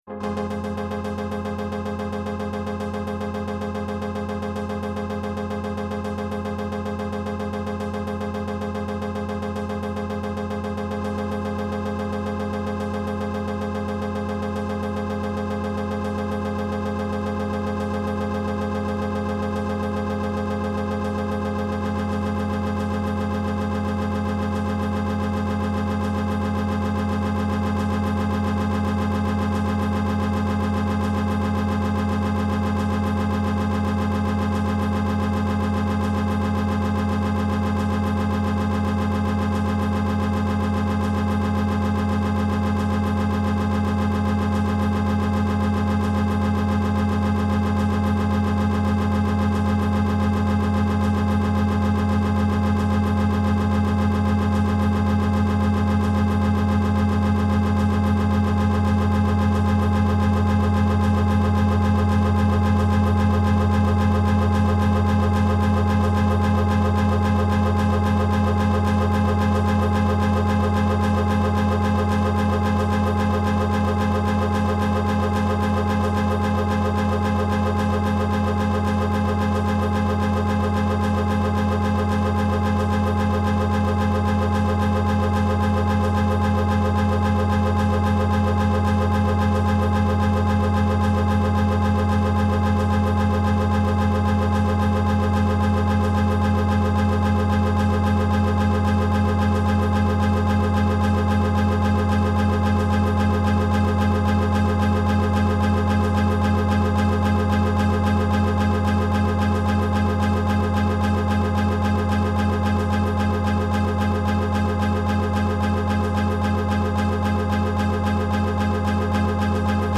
Synth_Obsession_8.mp3